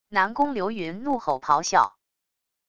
南宫流云怒吼咆哮wav音频